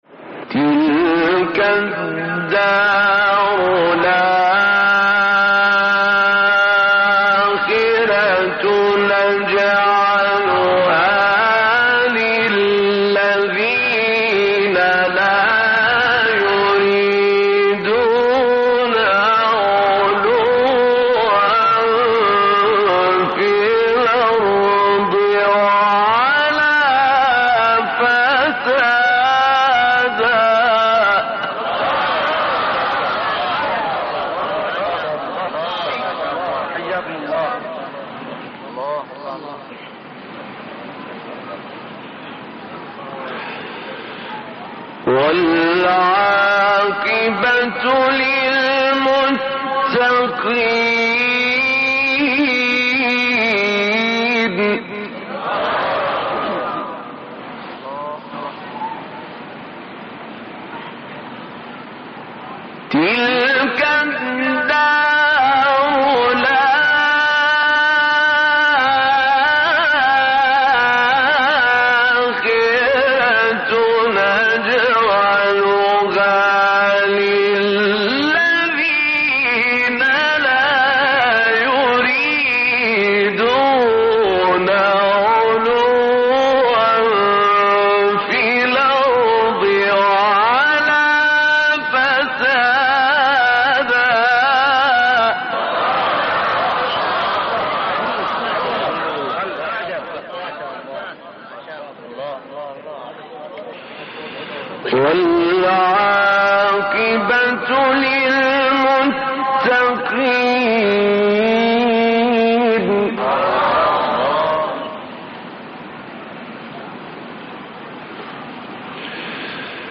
مقام رست سوره قصص استاد طاروطی | نغمات قرآن | دانلود تلاوت قرآن